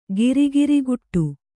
♪ giri giriguṭṭu